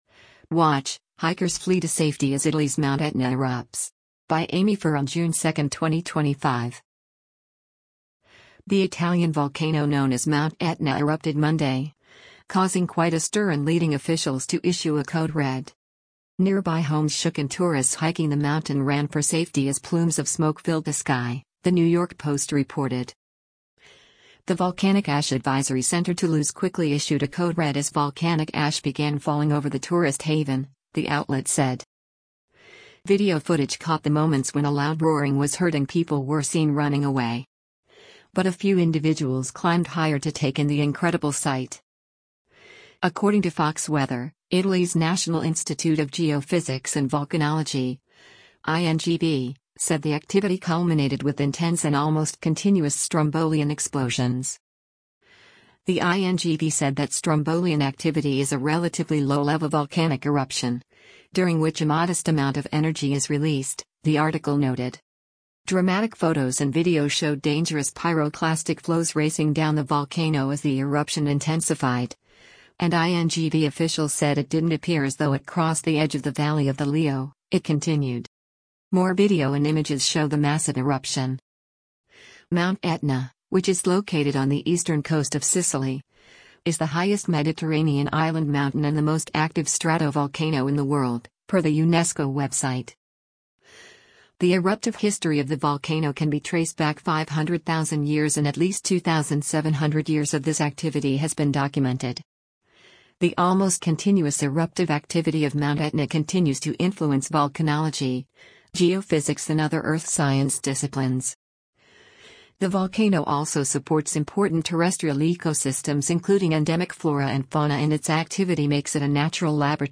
Video footage caught the moments when a loud roaring was heard and people were seen running away.